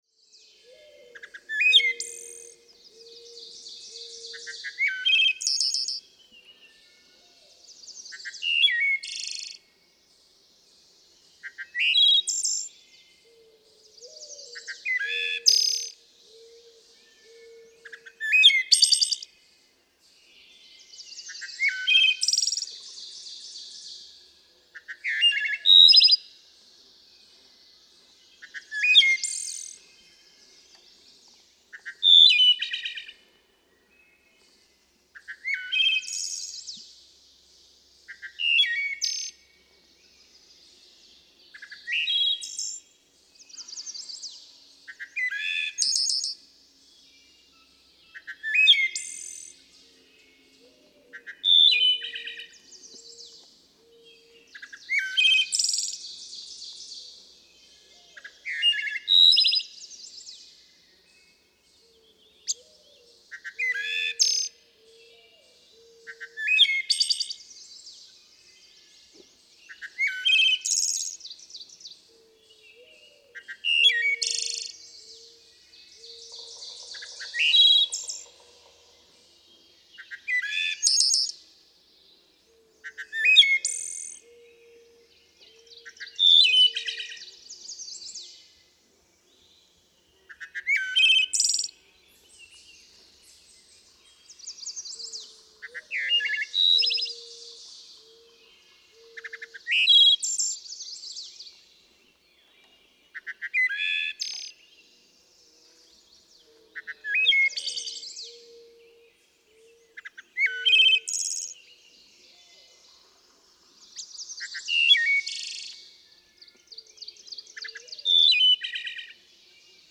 Wood thrush
♫440. Additional typical singing by the same male. May 10, 2007. Quabbin Park, Ware, Massachusetts. (6:44)
440_Wood_Thrush.mp3